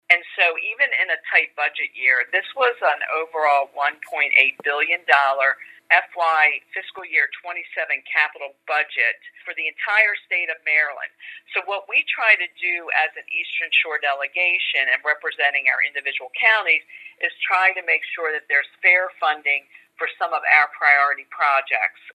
Senator Mary Beth Carozza tells the Talk of Delmarva that the Capital Budget is where they are able to support specific projects…